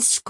Phoneme_(Umshk)_(Shk)_(Female).mp3